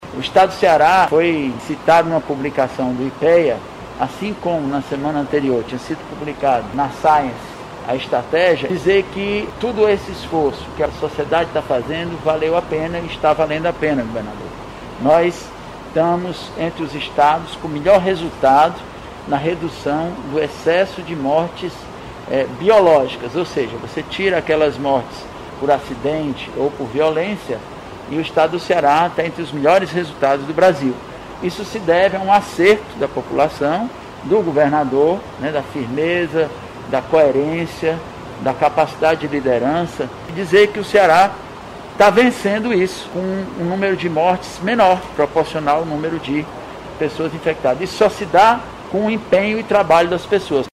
O secretário da Saúde, Doutor Cabeto, comentou estudos que apontam os resultados do Ceará na redução do número de mortes.